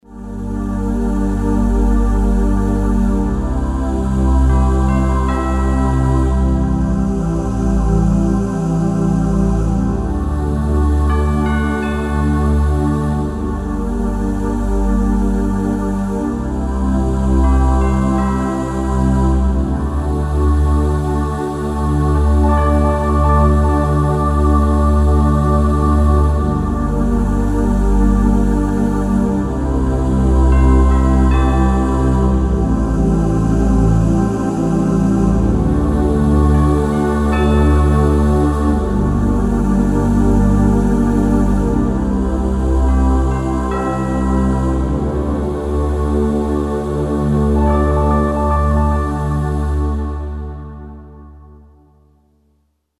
Choral1-4qq.mp3